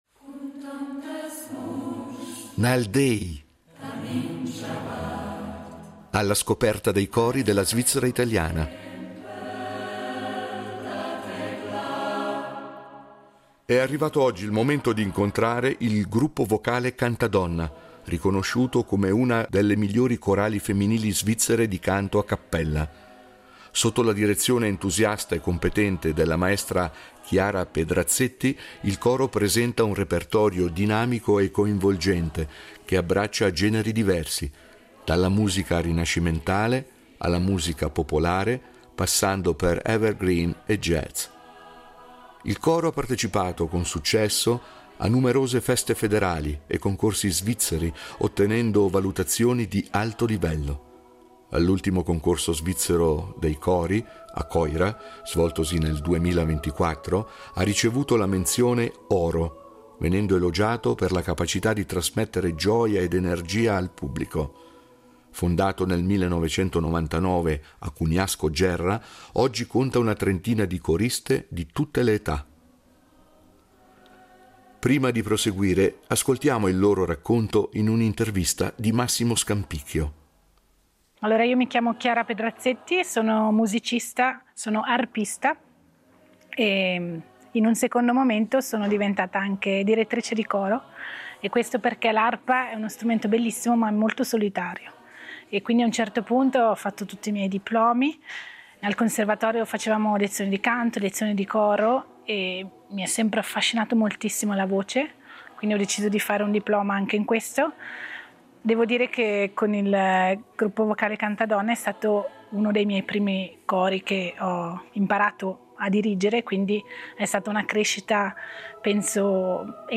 cori della svizzera italiana